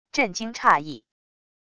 震惊诧异wav音频